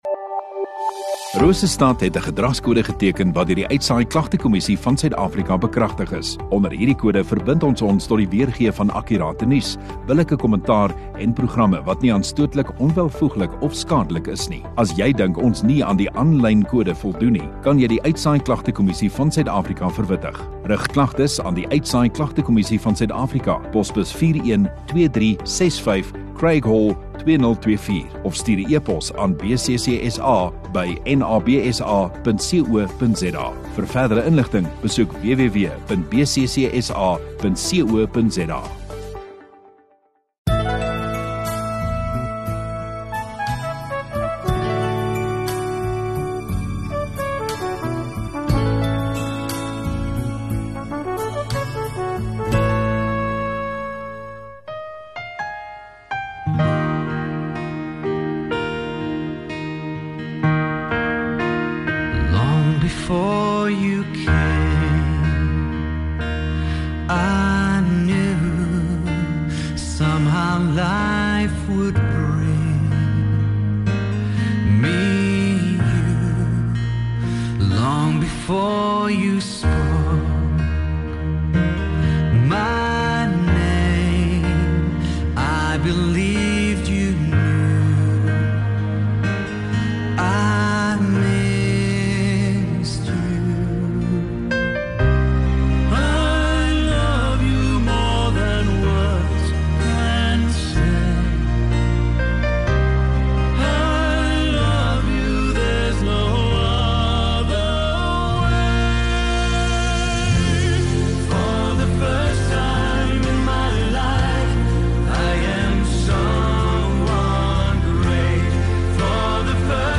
15 Feb Sondagaand Erediens